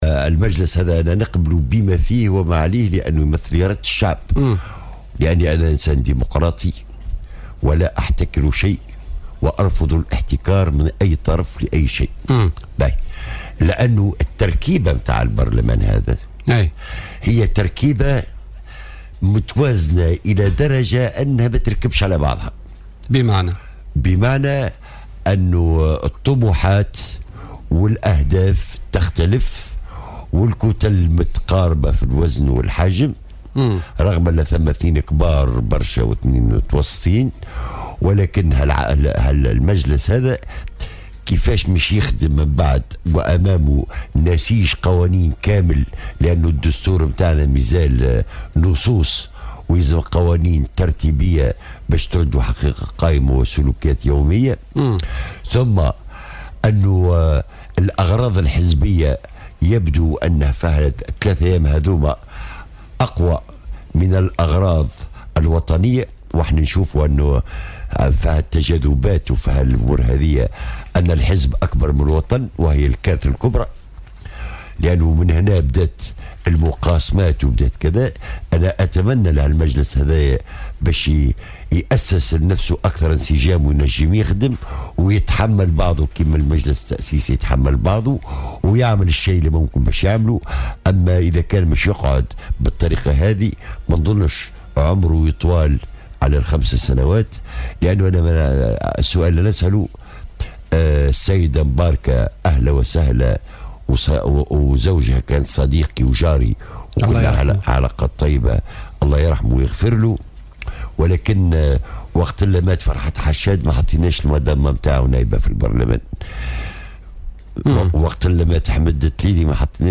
انتقد النائب السابق بالمجلس الوطني التأسيسي،الطاهر هميلة ضيف برنامج "بوليتيكا" اليوم الخميس وجود أرملة الشهيد محمد البراهمي،مباركة البراهمي في مجلس نواب الشعب الجديد.